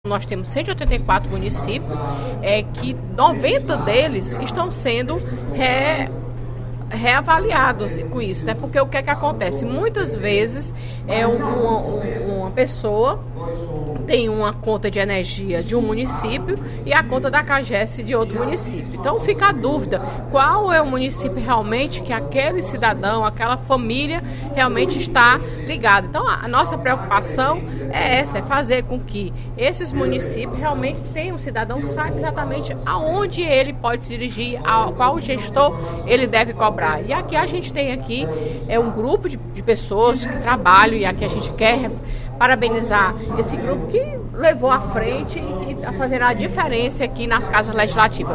A parlamentar falou no primeiro expediente da sessão plenária desta quarta-feira (17/02).
Dep. Fernanda Pessoa (PR) Agência de Notícias da ALCE